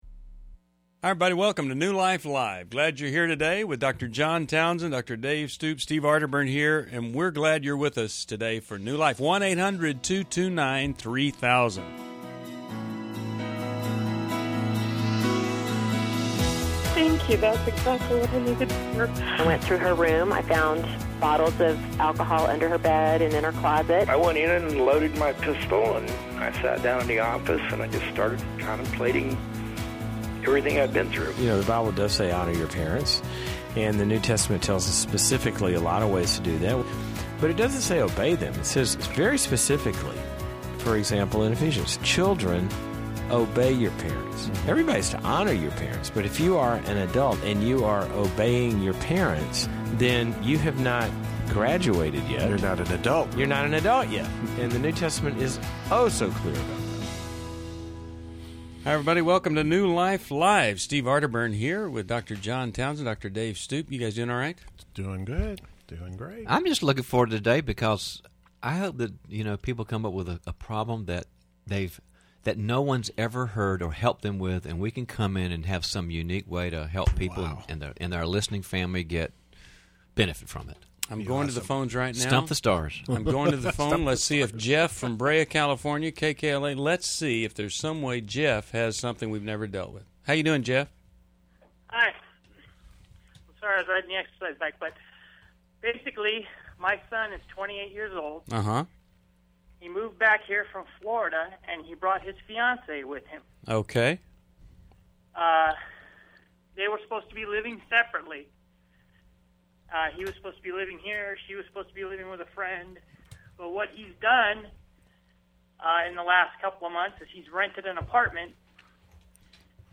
Caller Questions: 1.